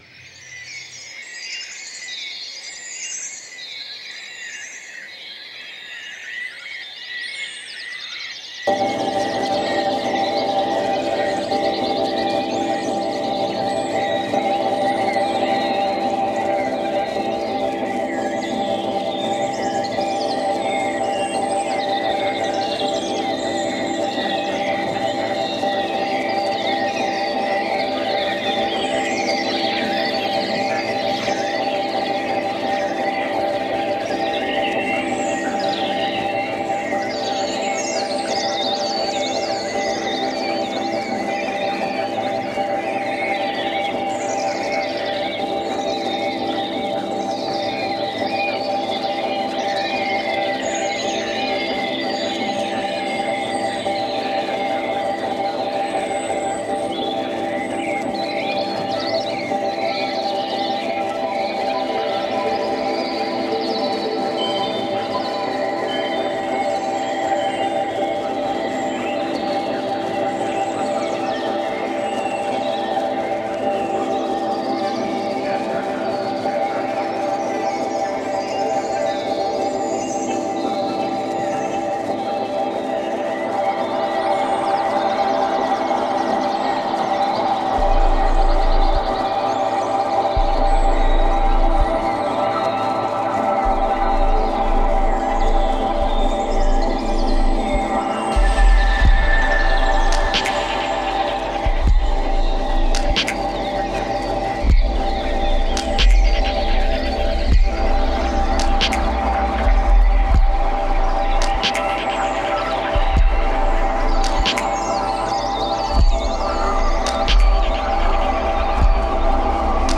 Beats Electronic Hip Hop